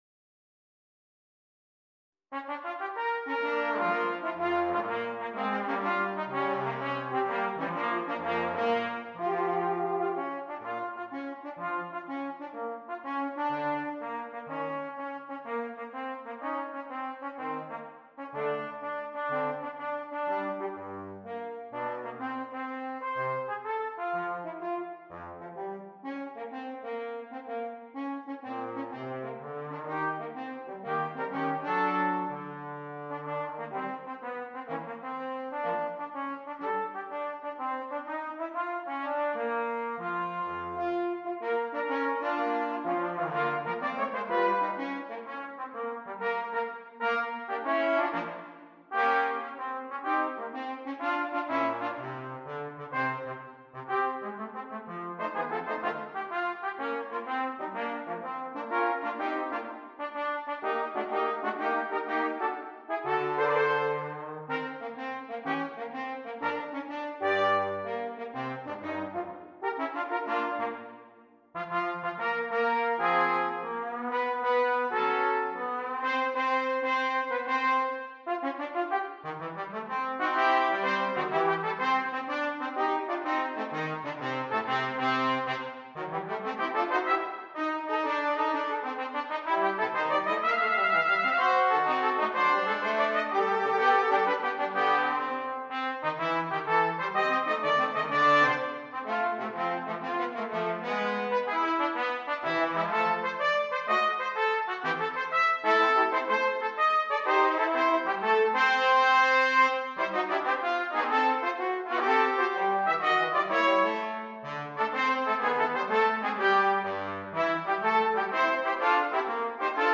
Brass Trio
This brass trio is often described with a single word: fun!